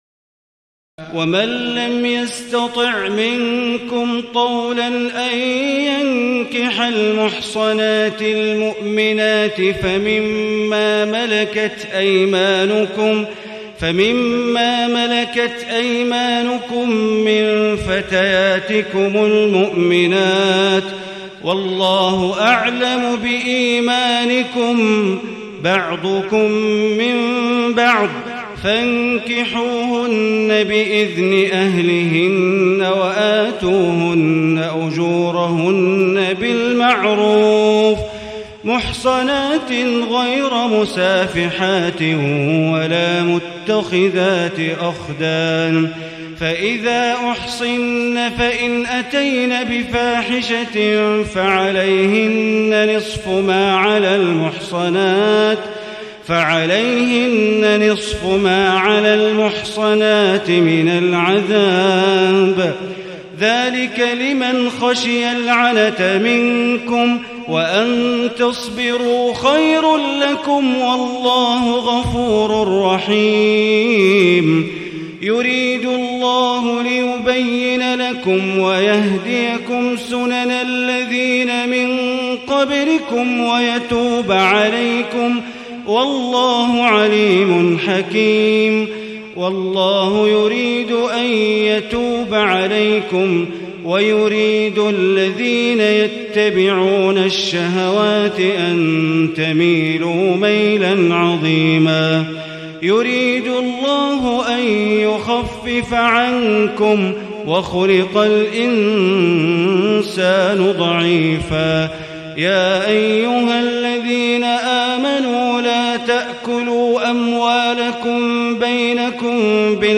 تهجد ليلة 25 رمضان 1437هـ من سورة النساء (25-99) Tahajjud 25 st night Ramadan 1437H from Surah An-Nisaa > تراويح الحرم المكي عام 1437 🕋 > التراويح - تلاوات الحرمين